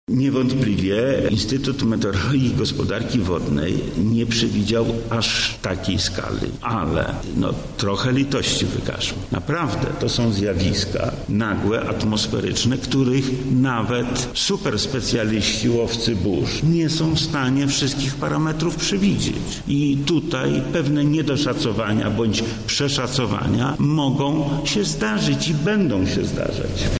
O tym, dlaczego mieszkańcy województwa nie otrzymali ostrzeżenia o nadchodzącej nawałnicy, mówi Wojewoda Lubelski Lech Sprawka: